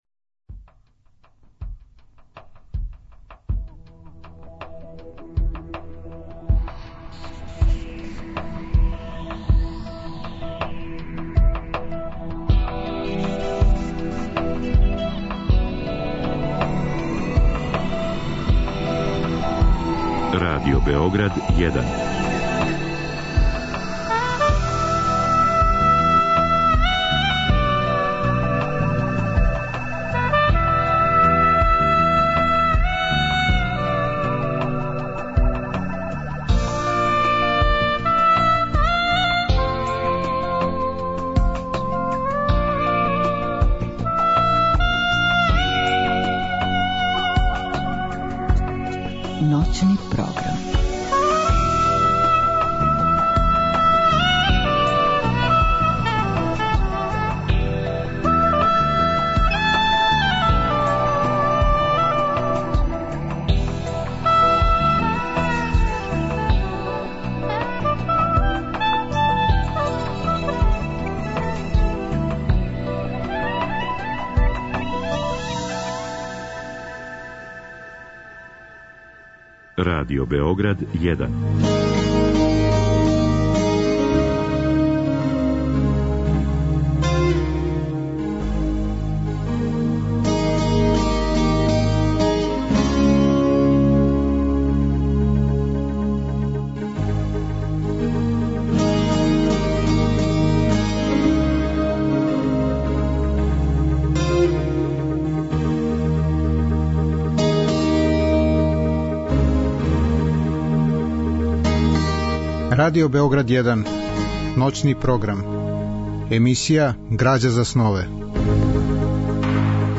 Разговор и добра музика требало би да кроз ову емисију и сами постану грађа за снове.
У другом делу емисије - од два до четири часа ујутро - слушаћемо одабране делове из радио-драма рађених по делима Миодрага Павловића.